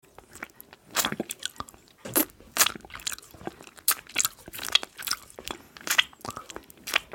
ASMR Lollipop Eating & Air sound effects free download
ASMR Lollipop Eating & Air Tracing Sounds